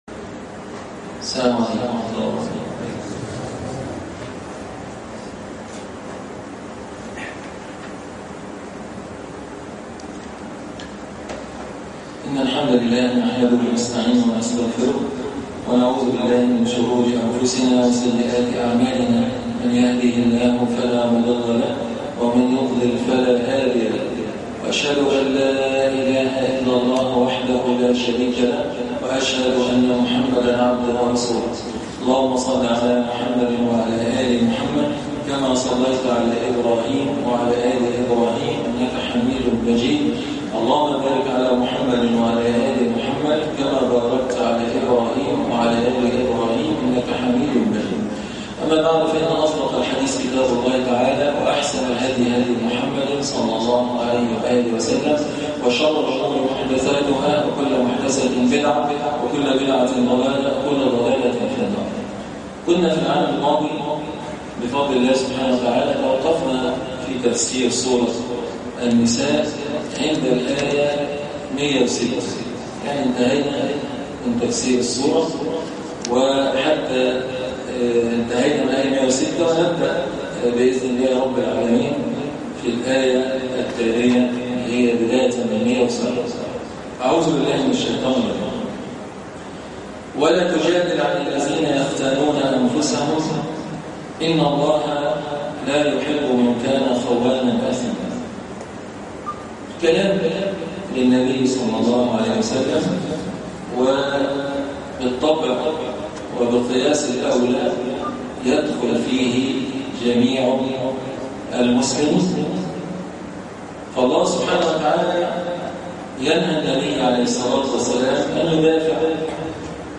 الدرس 44- الآيات (107-110) تفسير سورة النساء